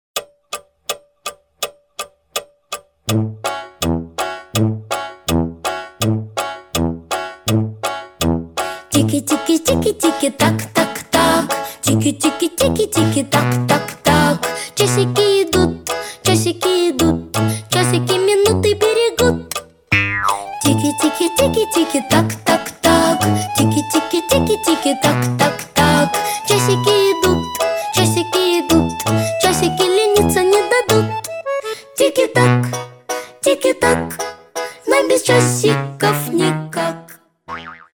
• Качество: 320, Stereo
забавные
веселые
детский голос
Детские песни